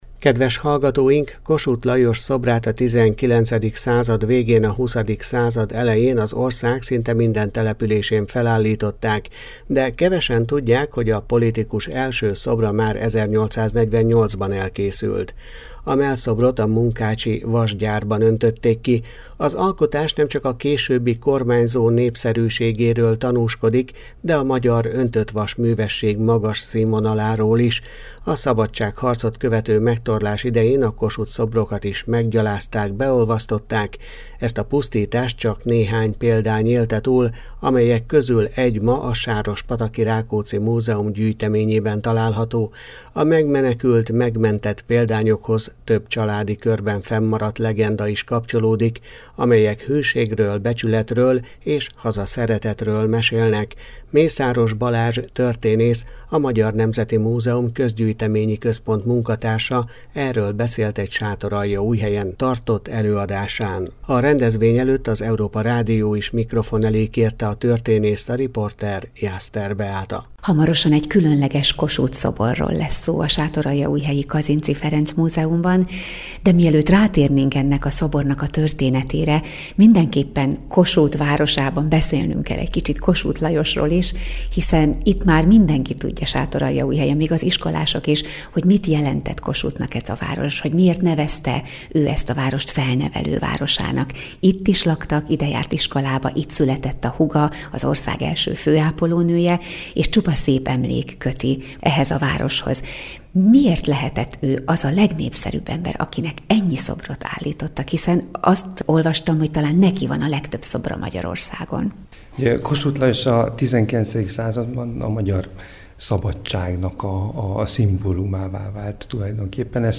Előadás egy ritka Kossuth szoborról